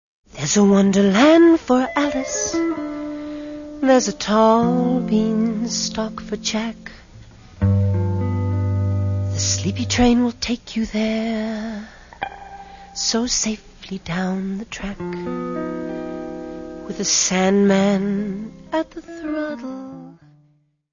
voz e guitarra
voz e bateria.
: stereo; 12 cm + folheto
Área:  Pop / Rock